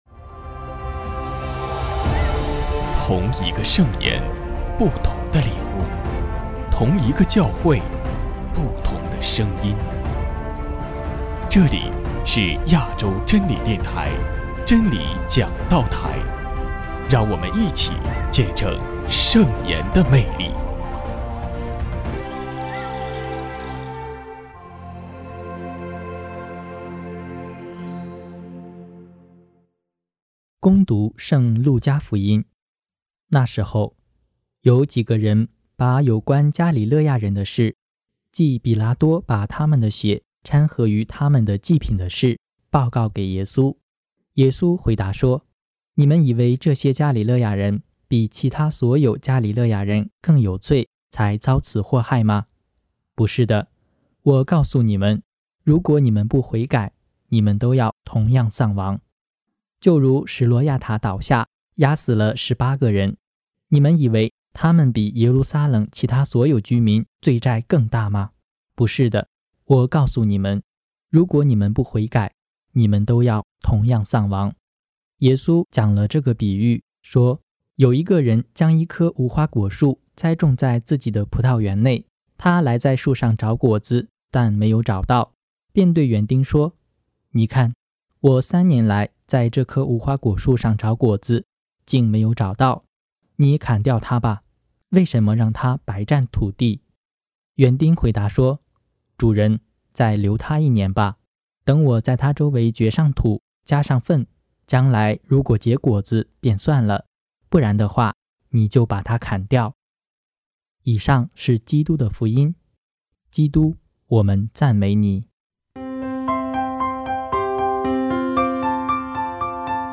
【真理讲道台 】64|四旬期第三主日证道